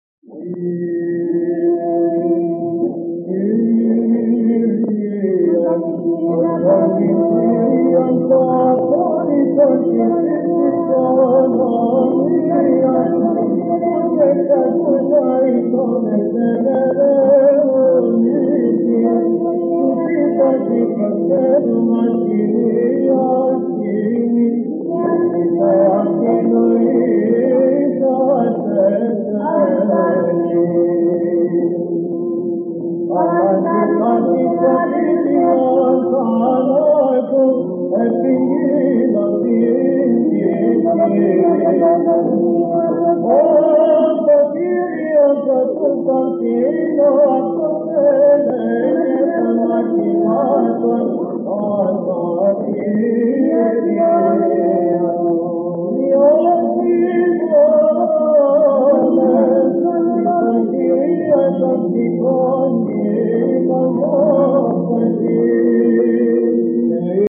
(ἠχογρ. Κυρ. Βαΐων ἑσπέρας)